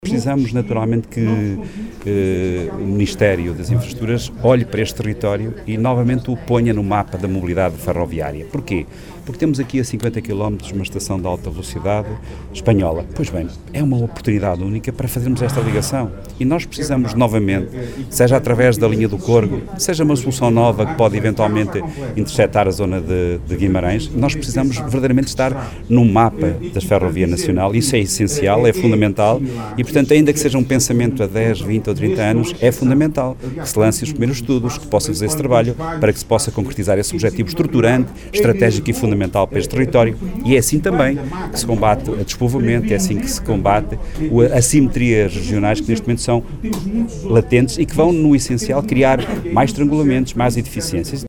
Logo em Chaves, o autarca socialista, Nuno Vaz, desafiou o Ministério das Infraestruturas a pôr a região no mapa da mobilidade ferroviária: